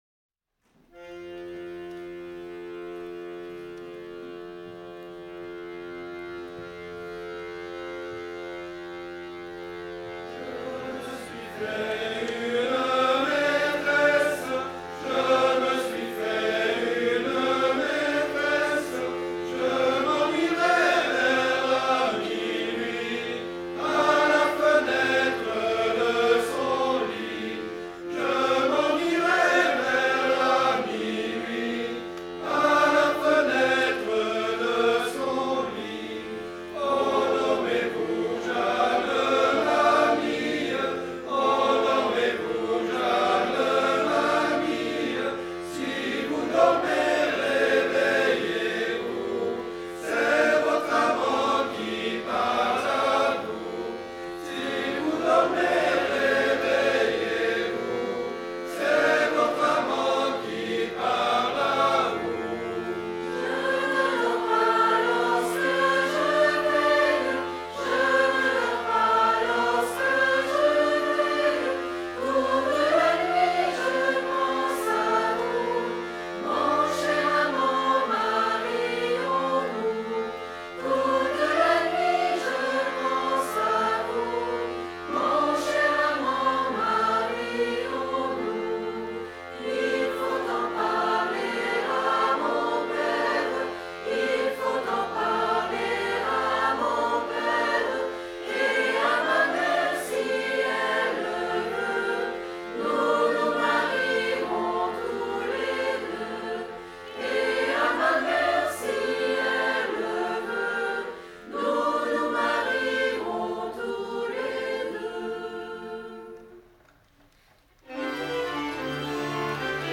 Sérénade ...